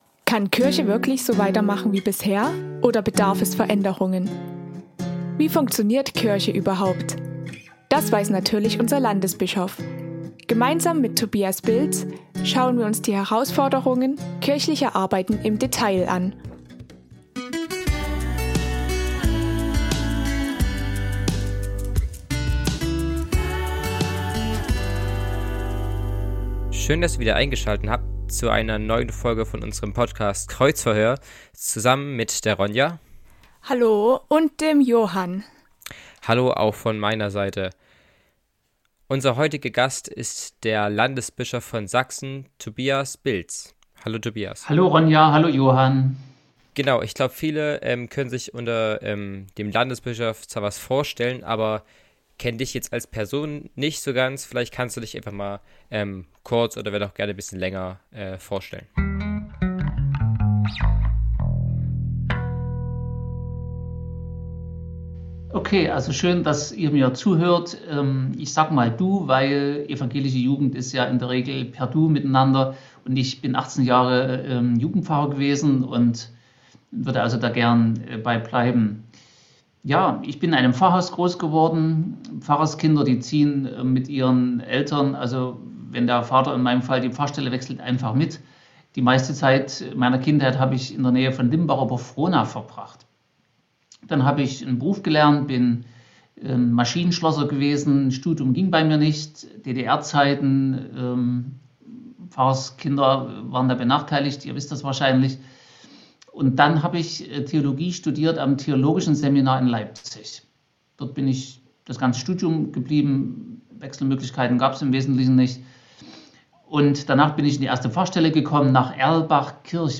Was Kirche falsch macht! | Tobias Bilz im Kreuzverhöhr